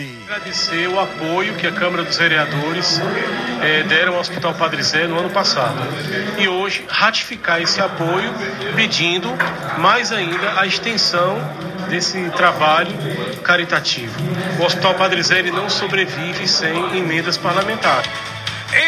conforme registrado pelo programa Arapuan Verdade, da Rádio Arapuan FM desta terça-feira (03/12).